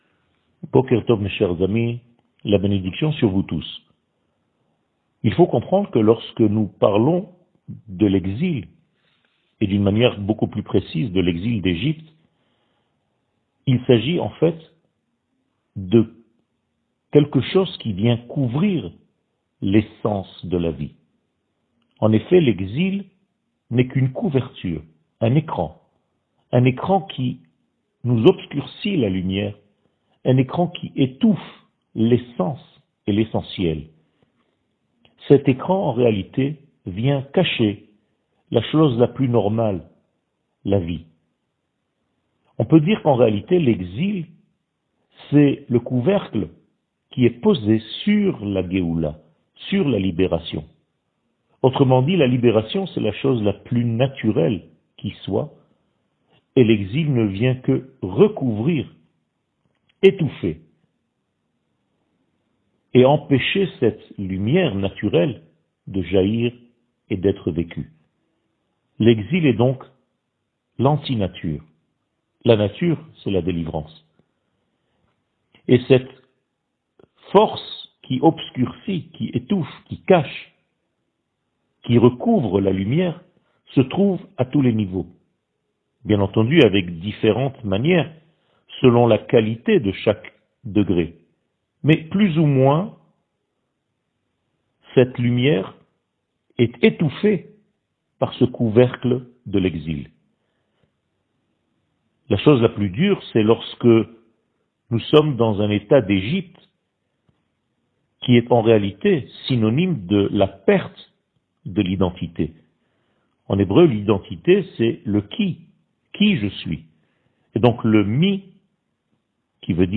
שיעור מ 04 ינואר 2022
שיעורים קצרים